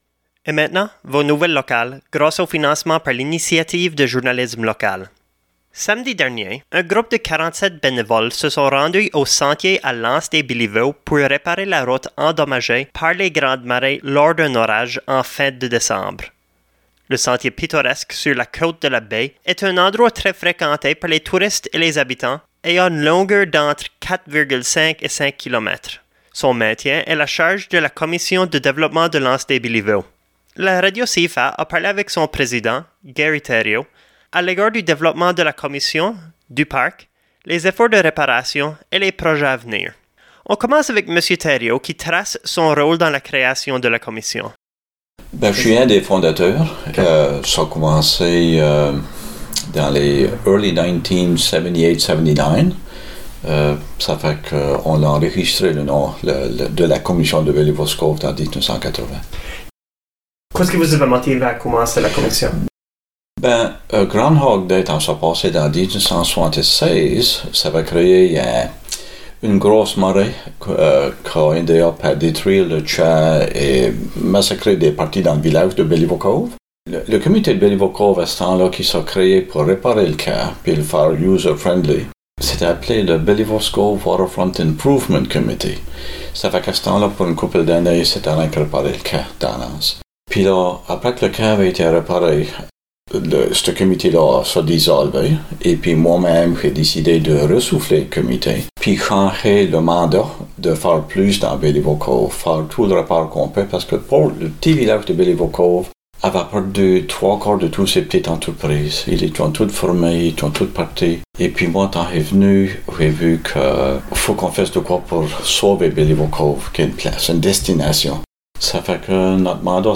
Nouvelles-locales-Benevoles-se-regroupent-pour-reparer-le-sentier-dans-lAnse-des-Belliveau-version-2.mp3